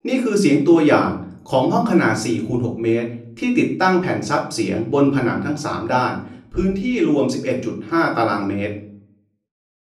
AFTER ACOUSTIC TREATMENT
Convoled_4_6_treated_room.wav